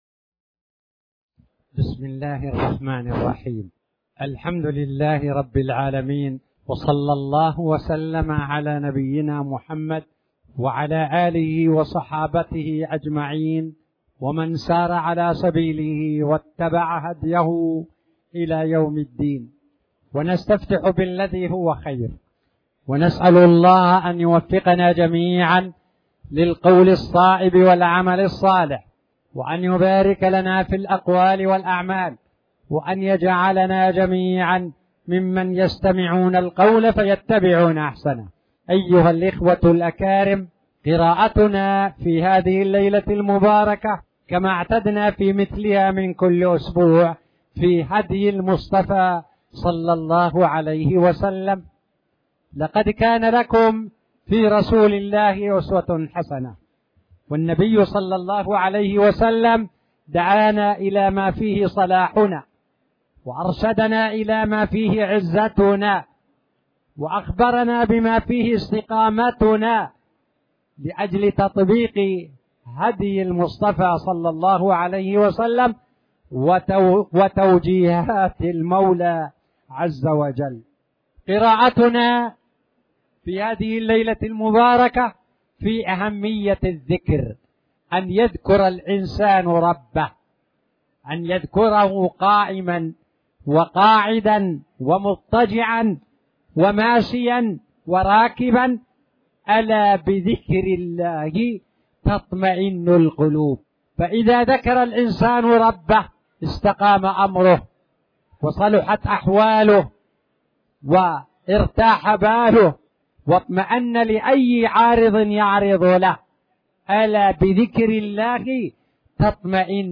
تاريخ النشر ٢٦ جمادى الأولى ١٤٣٨ هـ المكان: المسجد الحرام الشيخ